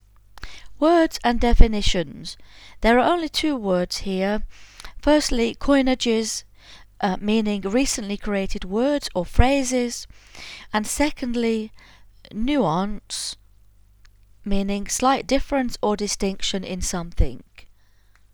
At the start of each article there are 2 recordings: the first are the words and definitions and the seond recording are the listen and repeat words.